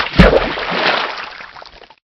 splash.ogg